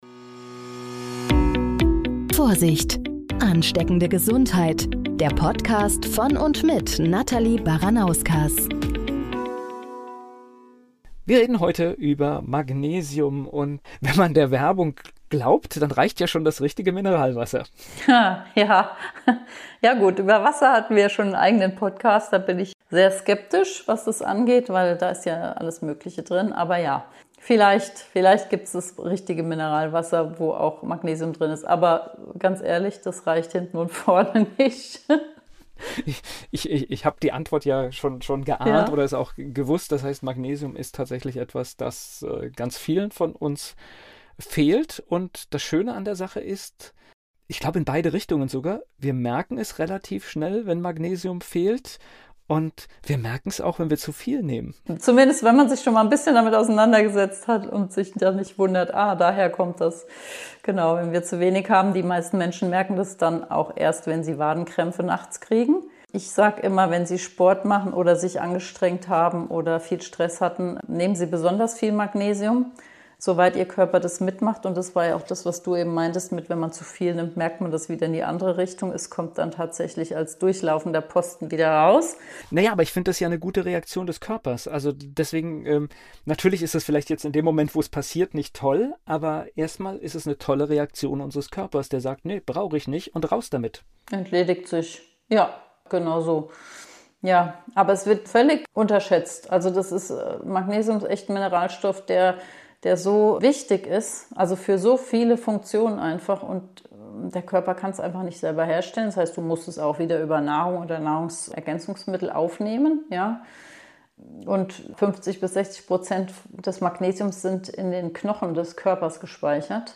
Ein Gespräch voller Aha-Momente rund um ein Mineral, das zu den stillen Superhelden unserer Gesundheit gehört.